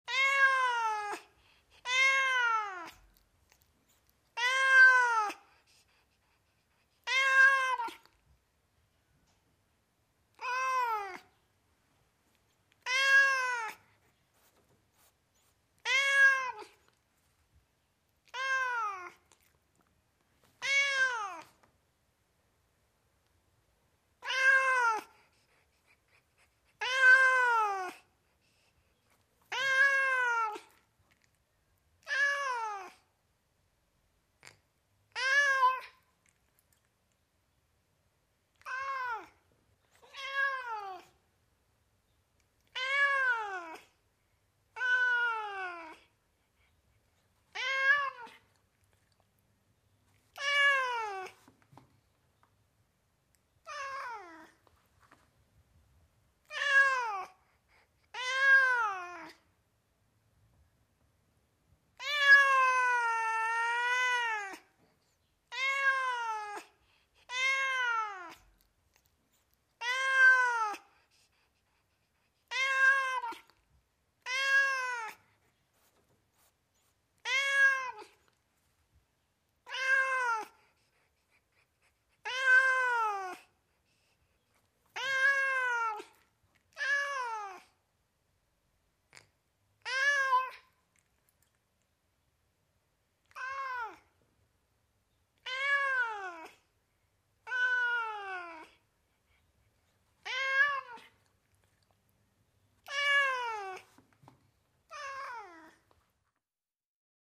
Звуки кошек
Звук мяуканья кошки (2)